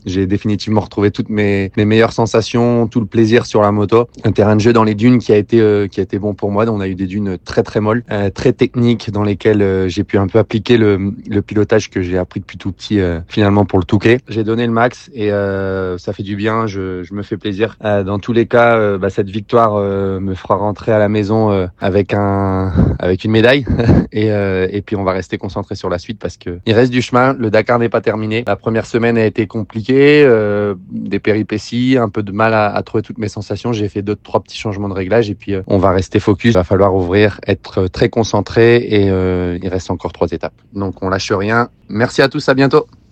Au bord des larmes , Adrien VANBEVEREN pour sa première victoire d’étape sur ce dakar 2026 . Après une semaine de galère , le pilote audomarois semble avoir retrouvé les bonnes sensations,LOGO HAUT PARLEUR il se confie ce matin au micro d’ NRJ